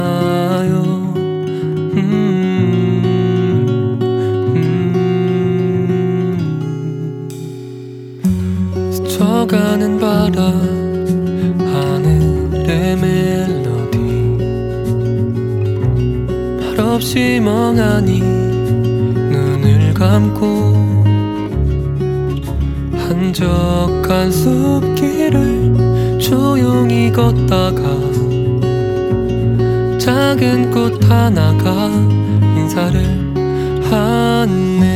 Жанр: Поп музыка
K-Pop